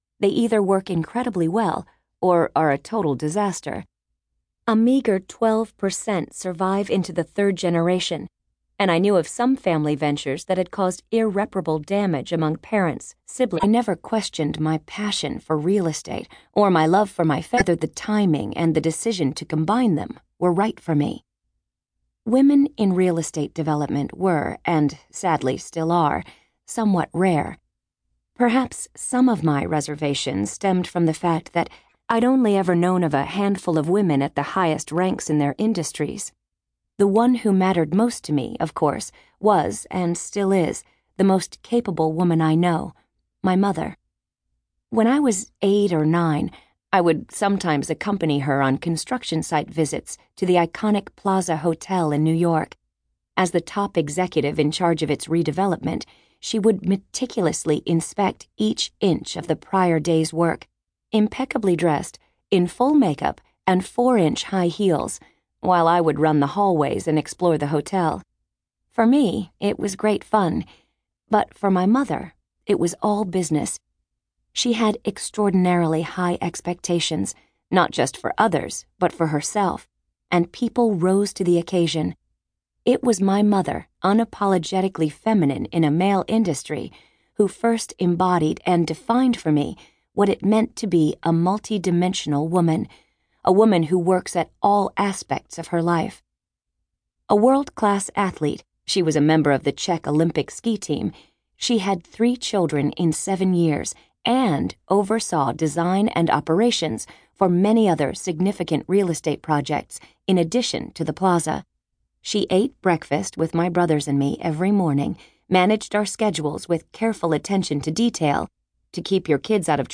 audiobook-sample.mp3